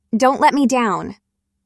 Ouça a pronúncia: